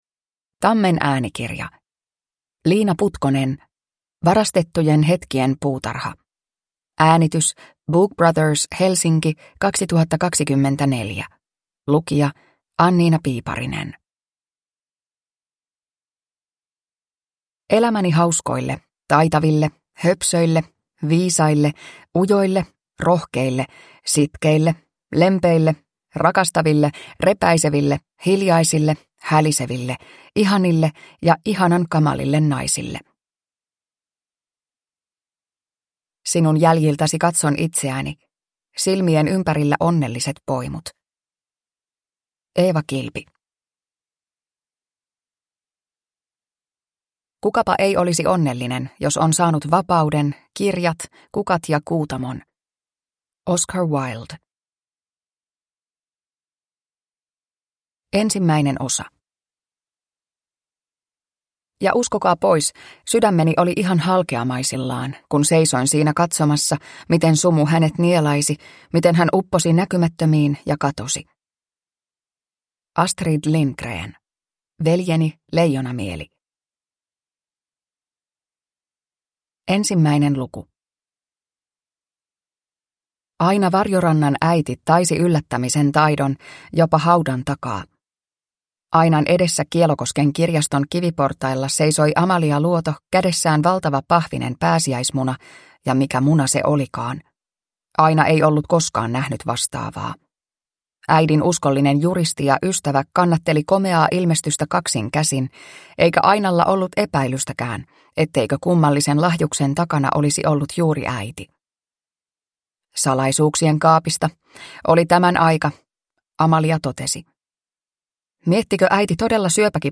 Varastettujen hetkien puutarha (ljudbok) av Liina Putkonen